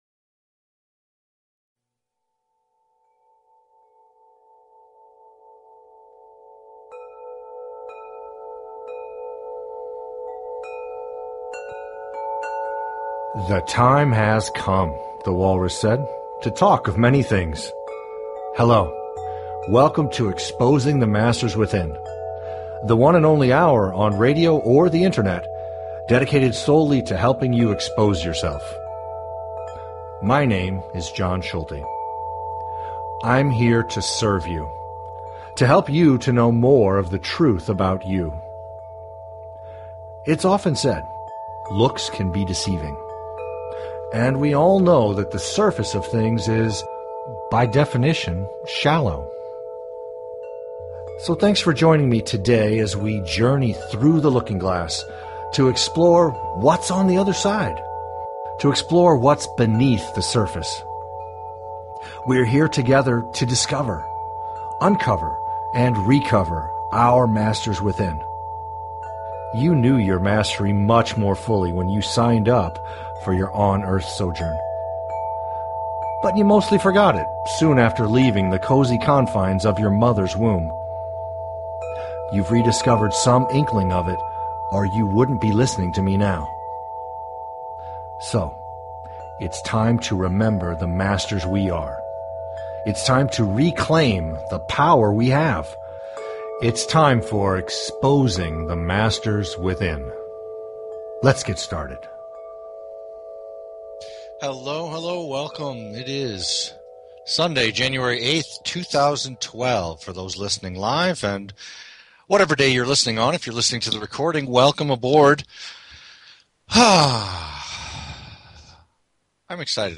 Talk Show Episode, Audio Podcast, Exposing_the_Masters_Within and Courtesy of BBS Radio on , show guests , about , categorized as